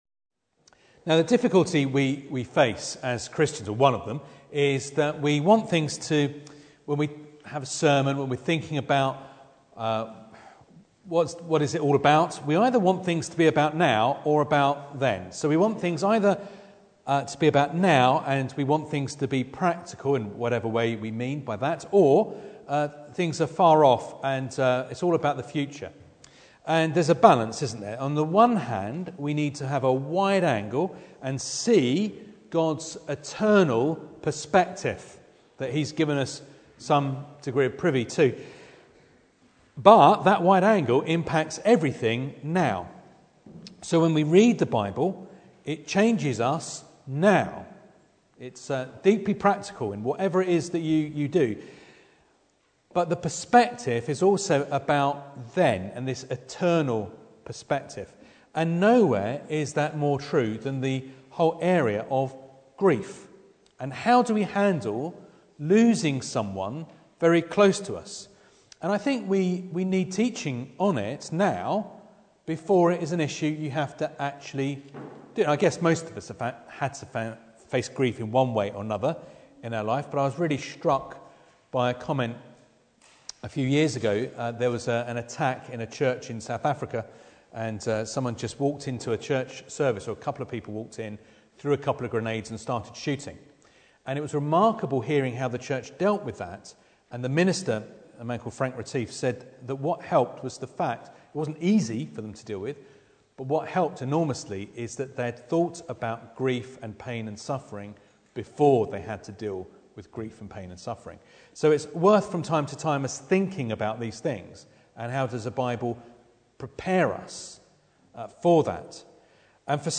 2 Samuel Passage: 2 Samuel 1 Service Type: Sunday Evening Bible Text